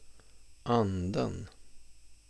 例：瑞典語中央方言 (Thorén 1997)
低聲: anden 精神 (